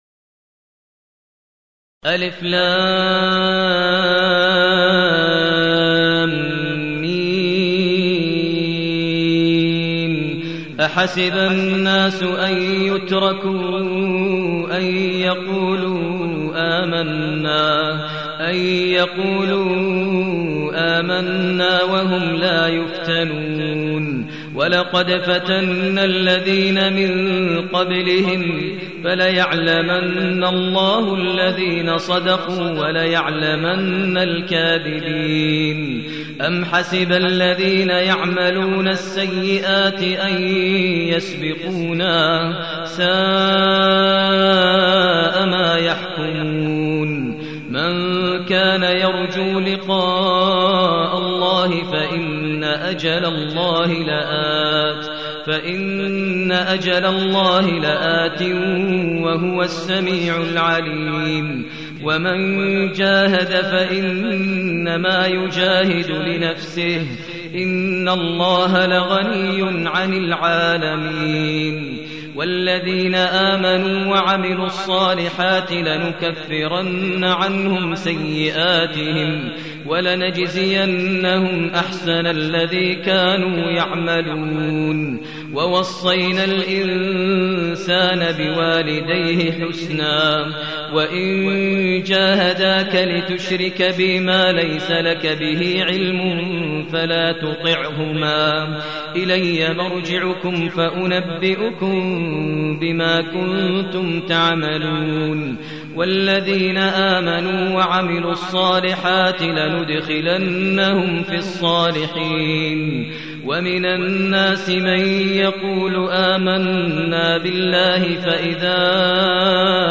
المكان: المسجد النبوي الشيخ: فضيلة الشيخ ماهر المعيقلي فضيلة الشيخ ماهر المعيقلي العنكبوت